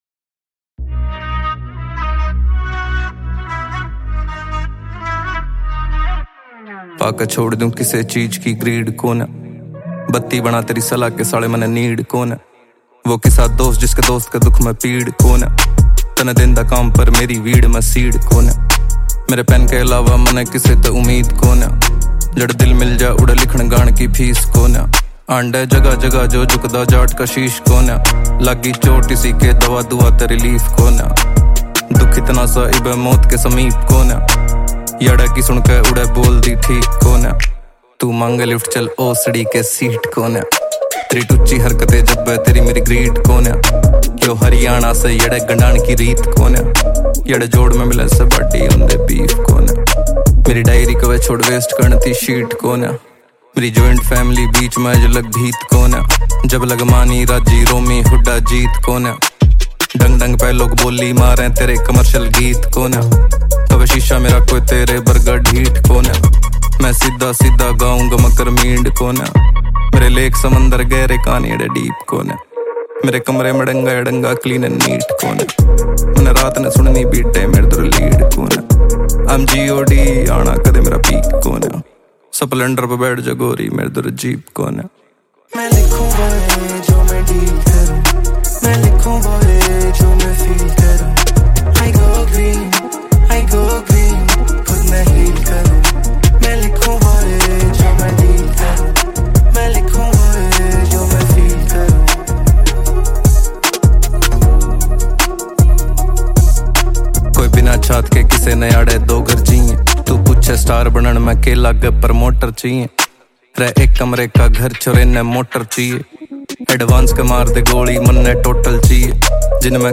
Category: Haryanvi